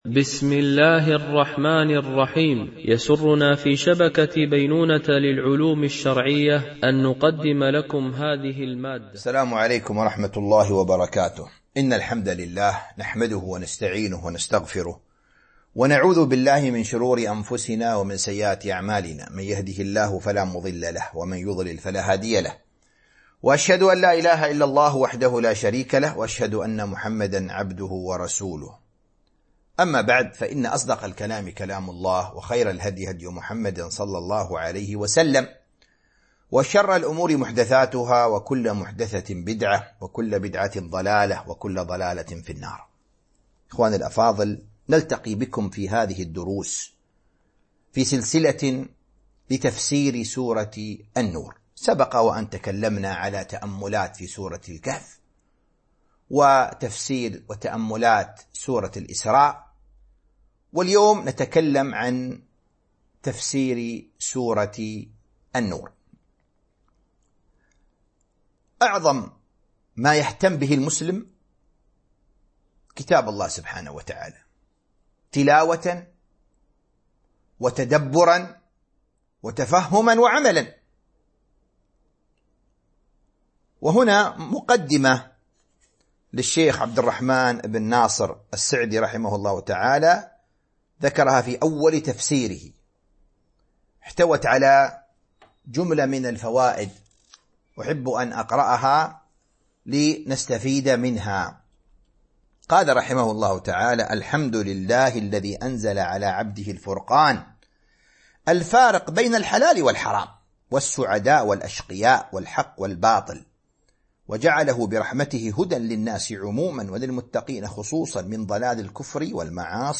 سلسلة محاضرات